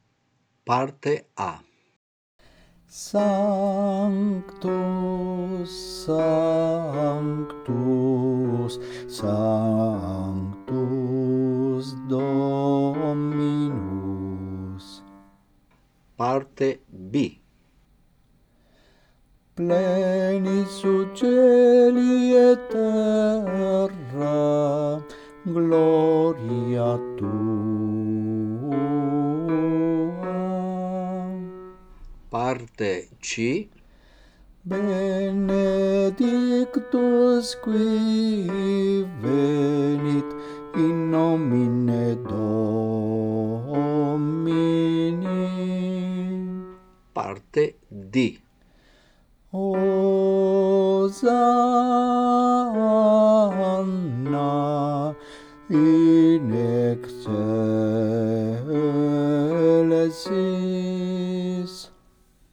Parte bassi